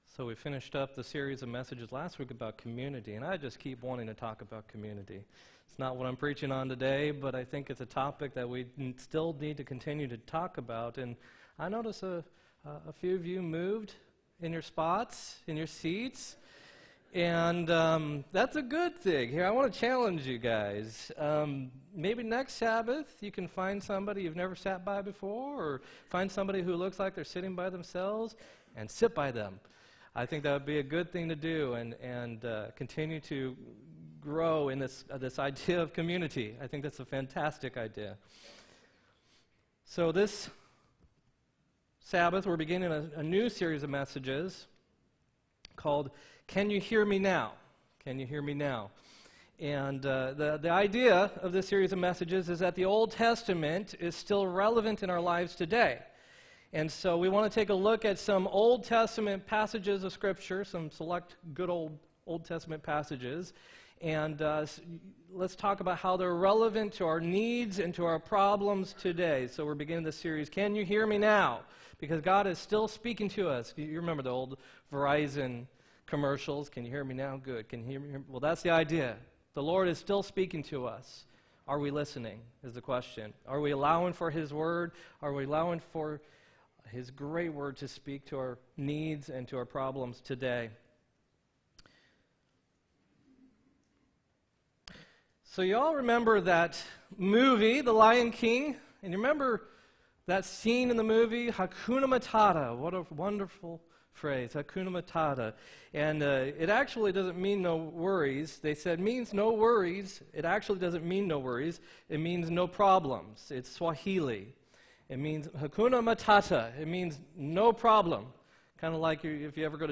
2-3-18 sermon2
2-3-18-sermon2.m4a